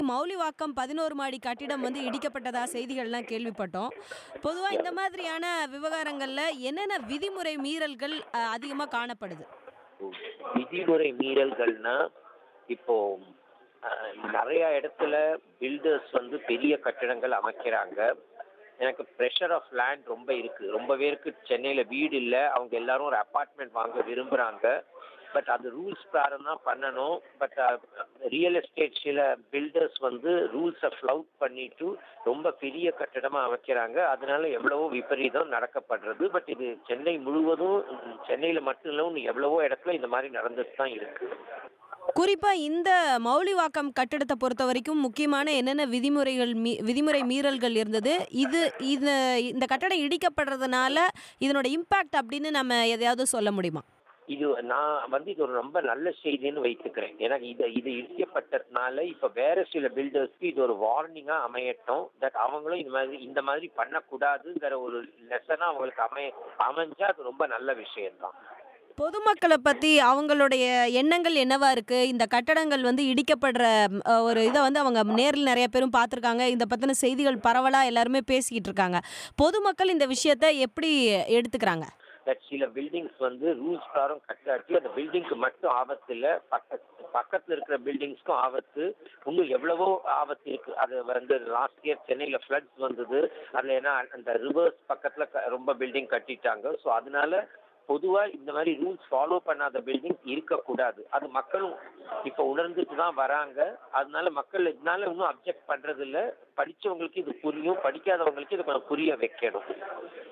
மவுலிவாக்கம் கட்டிடம் இடிக்கப்பட்டதன் விளைவு குறித்த பேட்டி